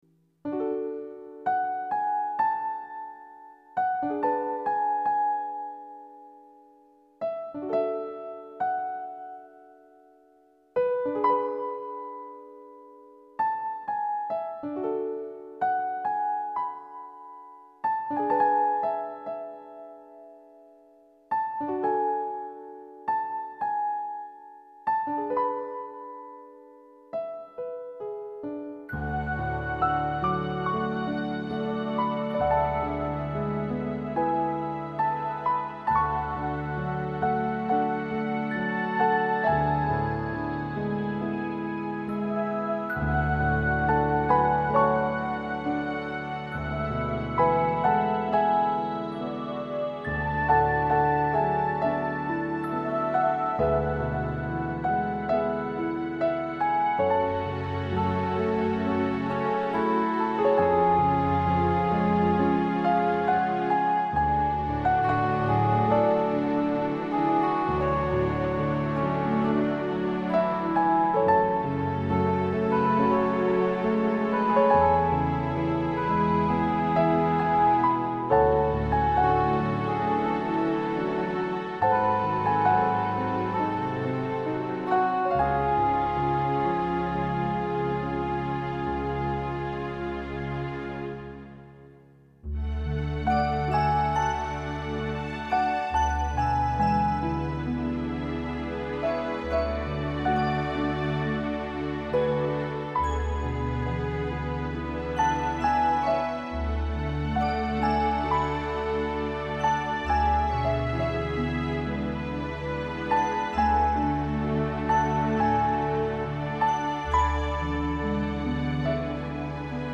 Music for relaxation and reflection